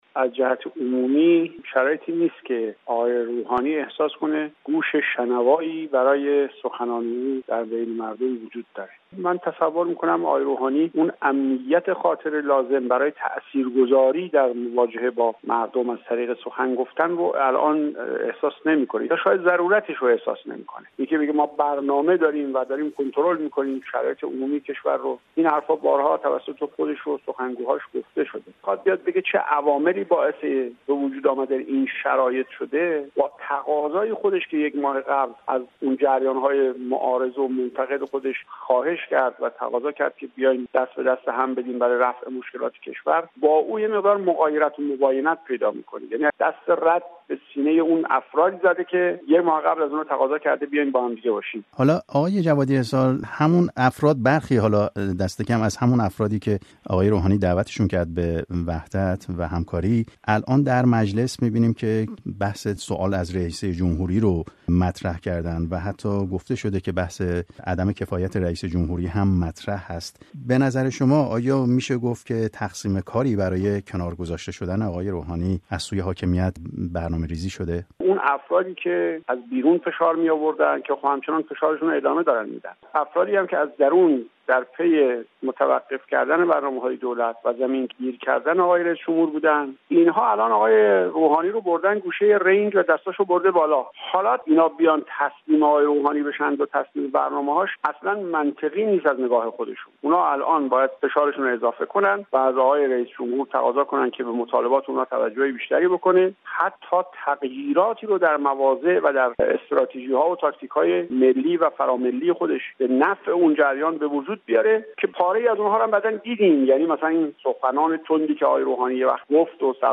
به رغم درخواست بسیاری از جریان‌های سیاسی در ایران از حسن روحانی برای شرکت در یک گفت‌وگوی زنده تلویزیونی و توضیح درباره وضعیت فعلی کشور، هنوز زمانی دقیقی برای انجام این گفت‌وگو مشخص نشده است. محمدصادق جوادی حصار، عضو حزب اعتماد ملی، درباره سکوت فعلی حسن روحانی به پرسش‌های رادیو فردا پاسخ داده است.